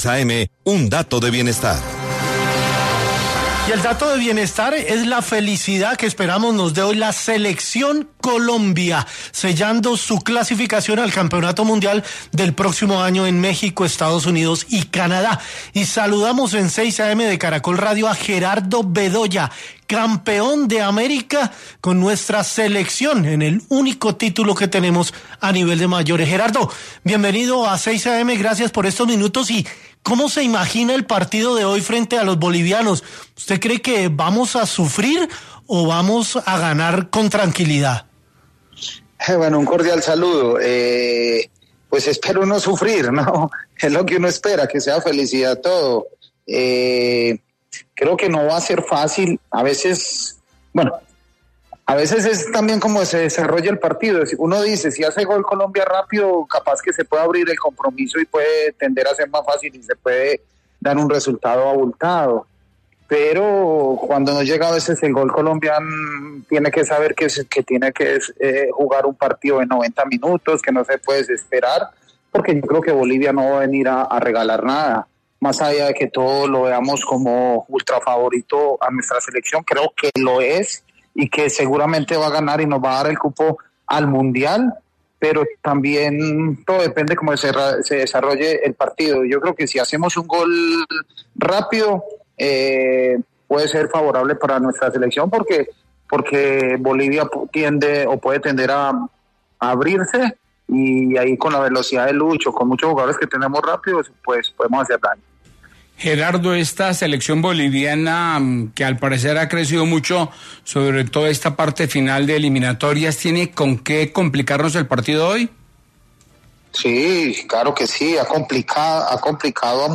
Para hablar del encuentro, 6AM de Caracol Radio con Gustavo Gómez, habló con Gerardo Bedoya, ex futbolista y actualmente técnico que dejó sus conceptos y predicciones de lo que puede pasar en el compromiso.